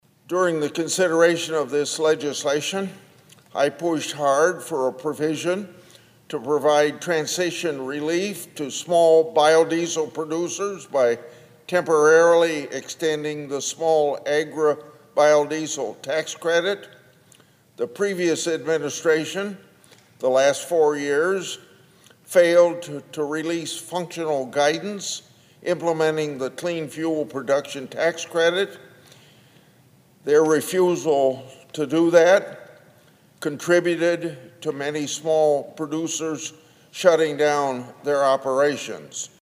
Grassley made his remarks on Wednesday on the floor of the U.S. Senate